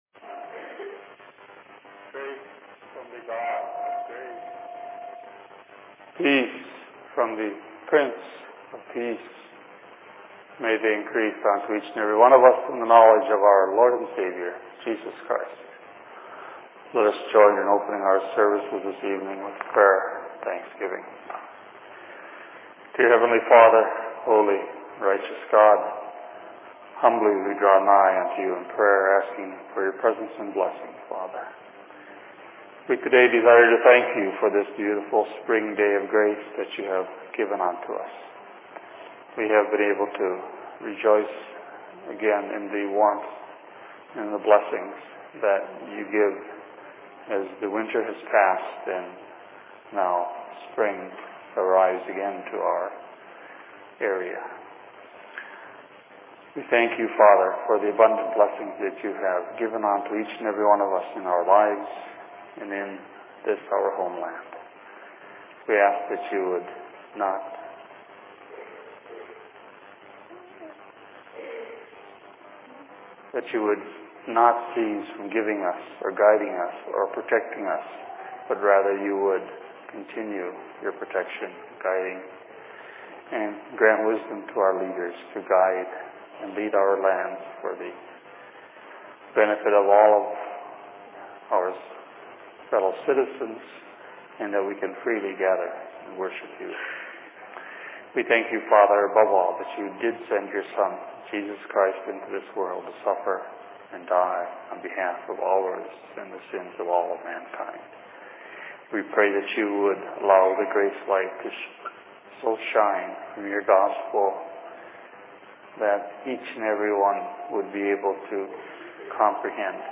Sermon in Minneapolis 17.04.2005
Location: LLC Minneapolis